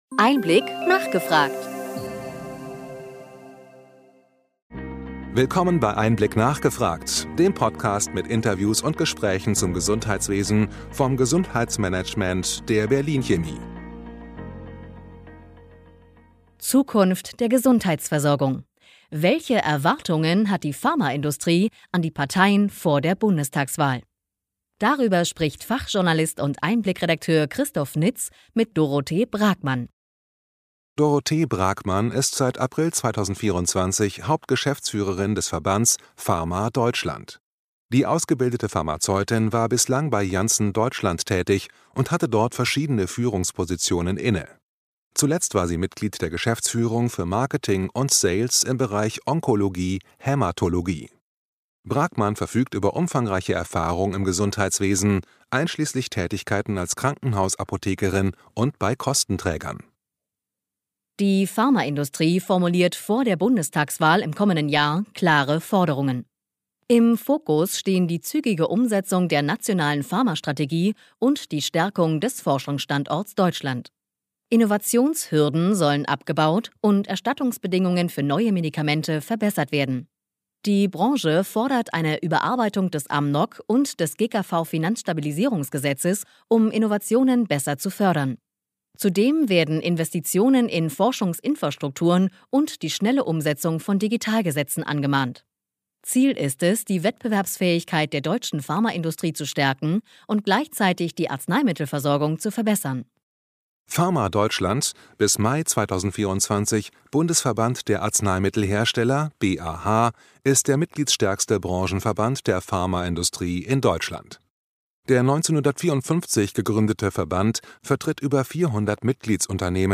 Beschreibung vor 1 Jahr EinBlick – nachgefragt Podcast mit Interviews und Diskussionsrunden mit Expert:innen des Gesundheitswesens Zukunft der Gesundheitsversorgung. Welche Erwartungen hat die Pharmaindustrie an die Parteien vor der Bundestagswahl?